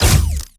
Plasmid Machinegun C
GUNAuto_Plasmid Machinegun C Single_04_SFRMS_SCIWPNS.wav